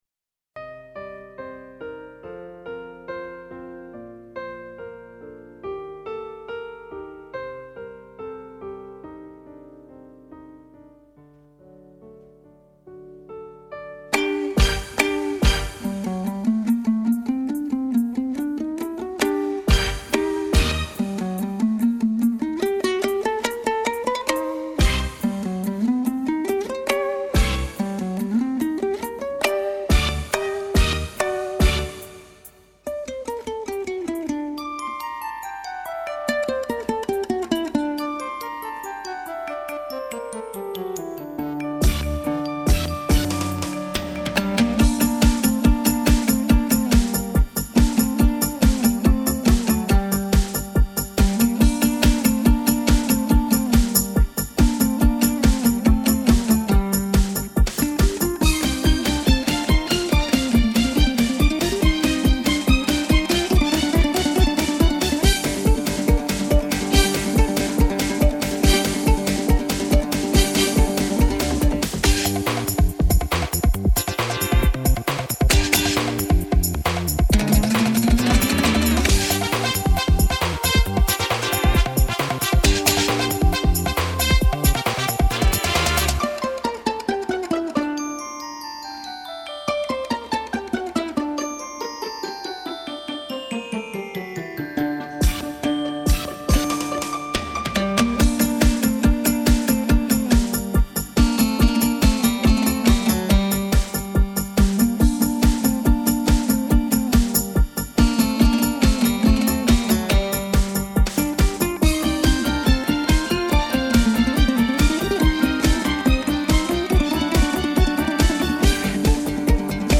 домбра